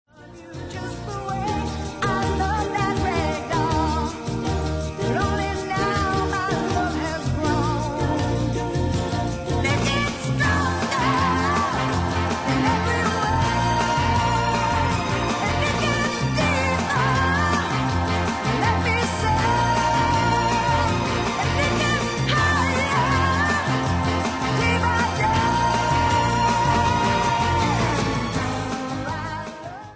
w/vocal